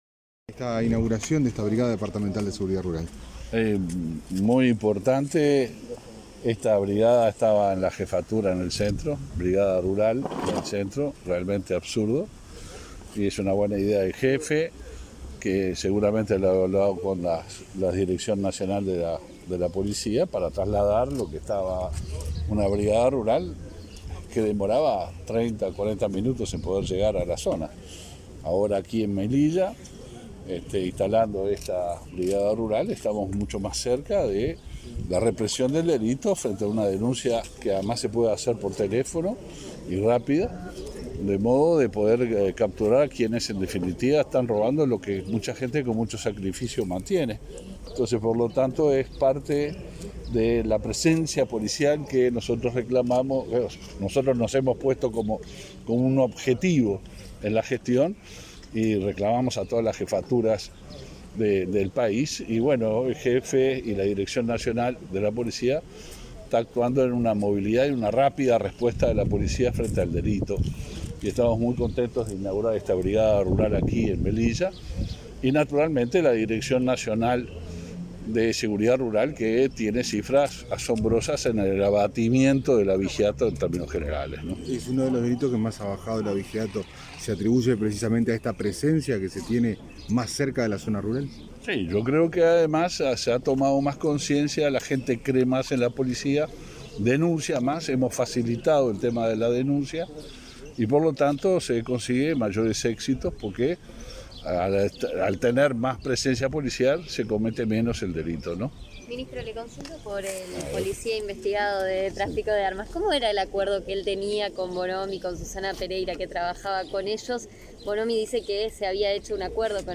Declaraciones a la prensa del ministro del Interior, Luis Alberto Heber
Tras participar en la inauguración del local de Brigada de Seguridad Rural de Montevideo, este 5 de noviembre, el ministro efectuó declaraciones a la